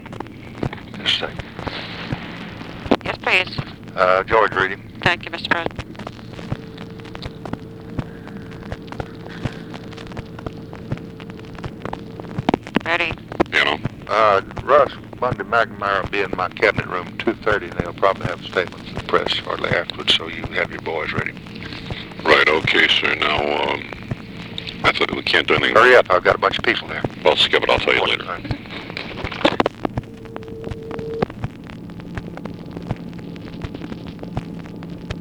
Conversation with GEORGE REEDY, September 18, 1964
Secret White House Tapes